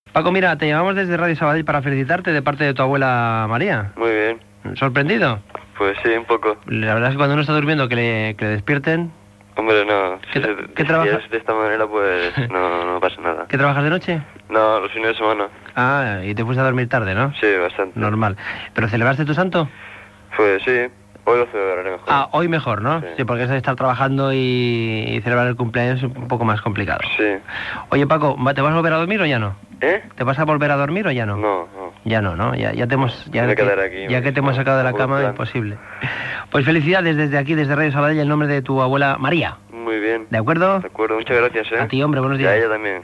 Trucada per felicitar a un oient
Entreteniment